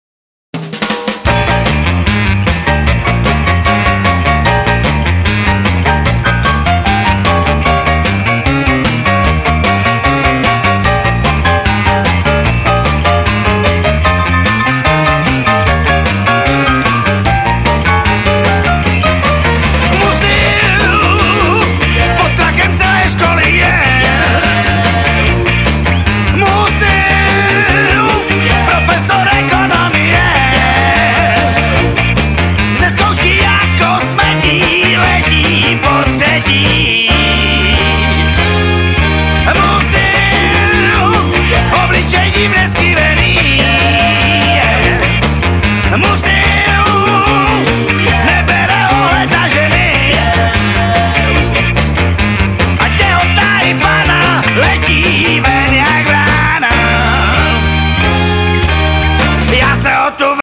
Rock’n’Roll!